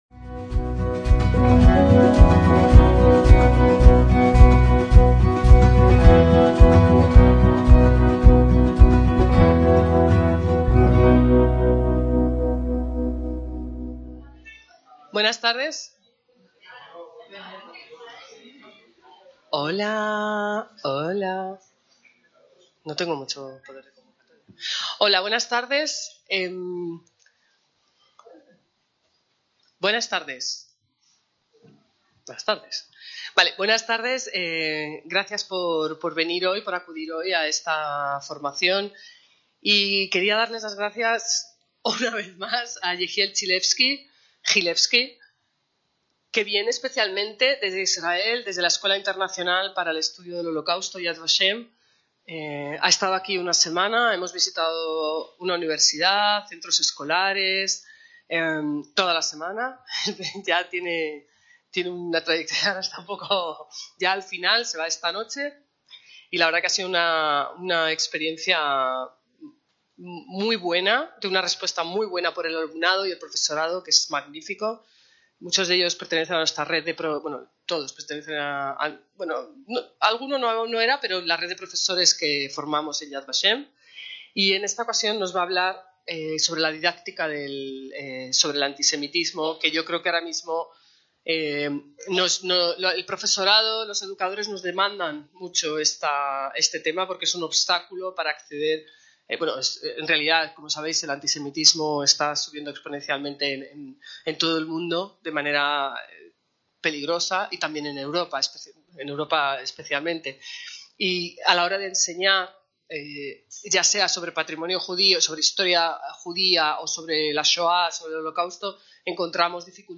Enseñando sobre el antisemitismo: Consideraciones pedagógicas (Centro Sefarad Israel, Madrid, 21/11/2024)
ACTOS EN DIRECTO